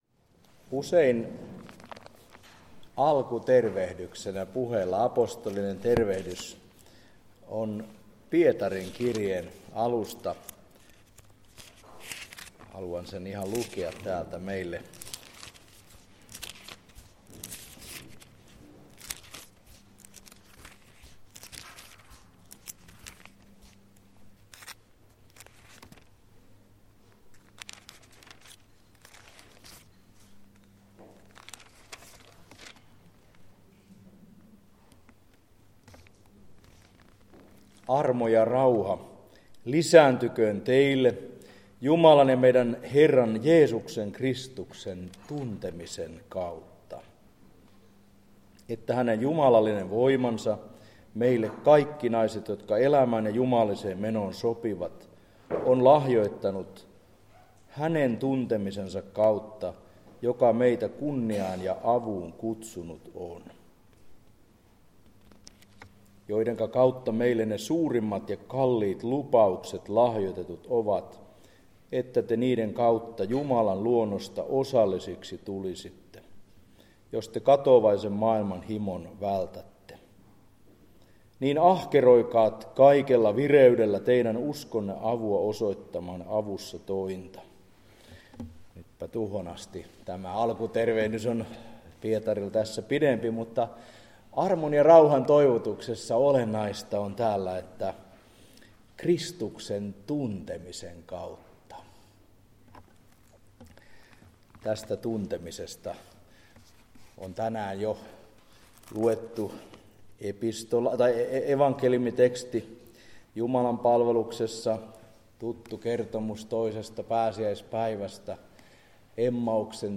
Jumalanpalvelus